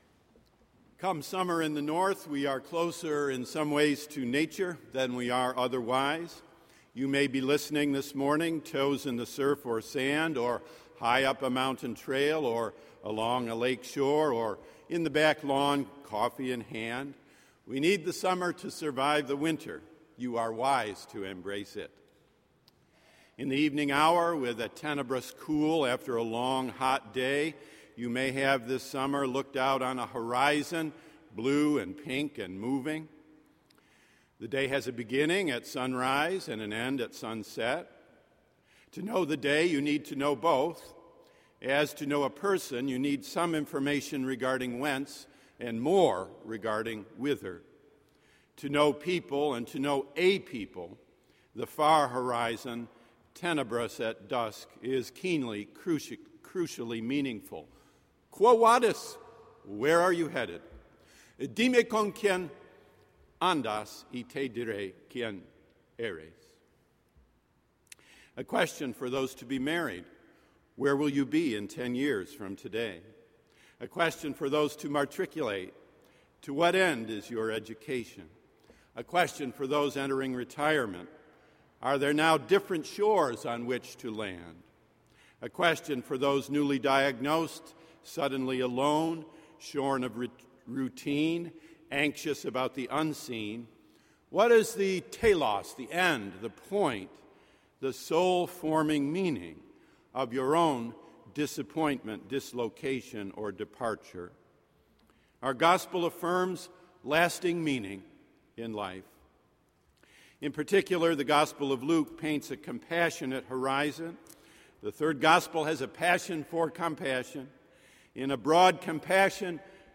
Marsh Chapel Sermon Archive » Boston University Blogs